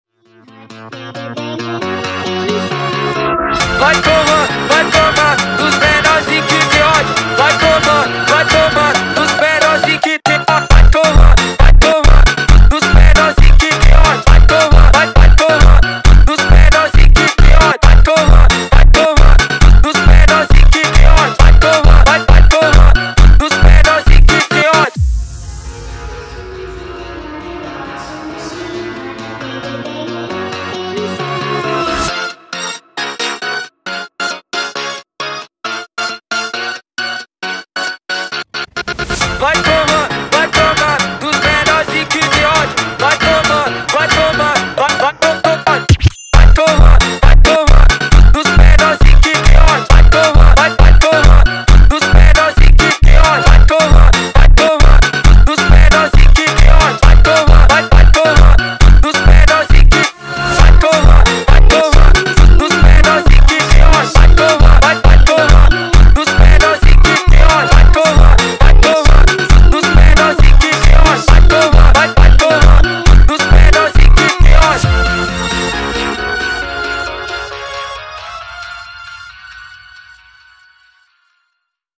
با ریتمی تند
فانک